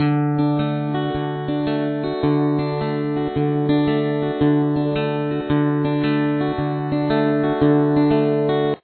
What you are playing below is just a D chord.